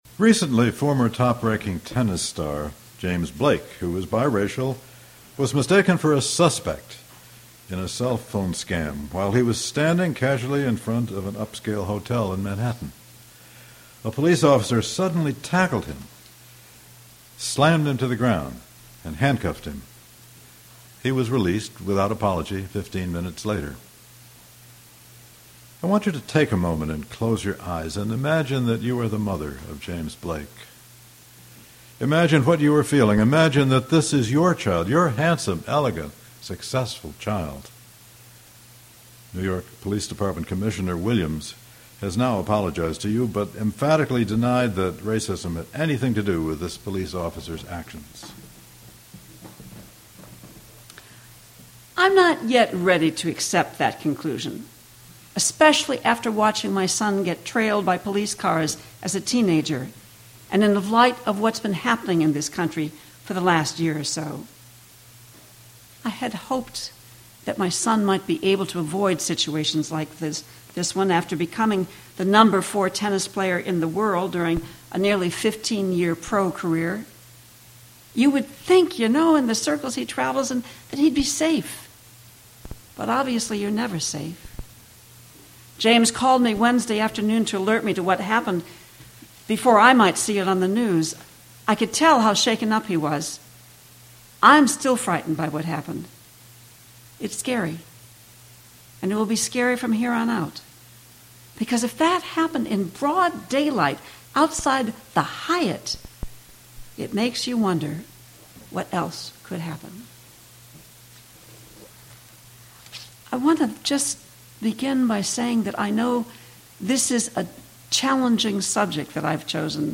This sermon explores the systemic injustices faced by African Americans and other marginalized groups while emphasizing the inherent sanctity of every human life.